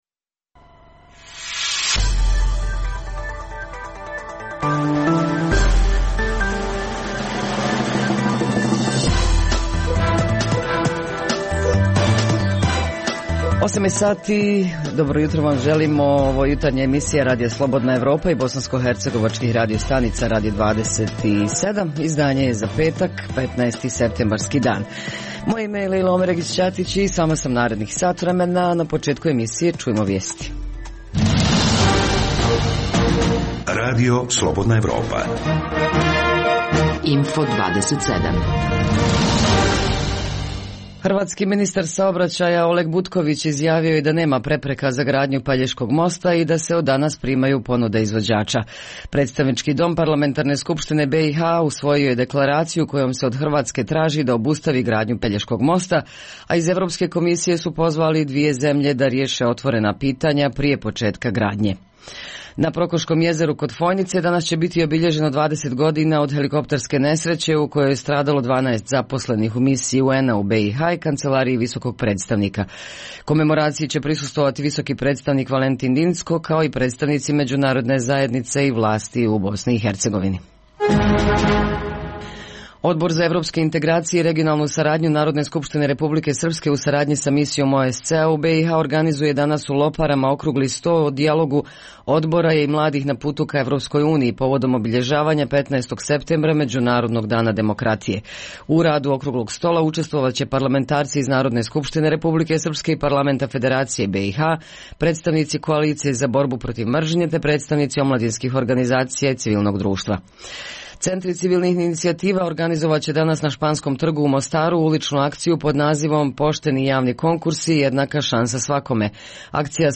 Info plus: O sajmu pčelarstva u Brčkom, opasnosti od mišije groznice u Bijeljini – i naravno drugim temama iz ta dva grada, informisaće nas naši dopisnici u javljanju uživo.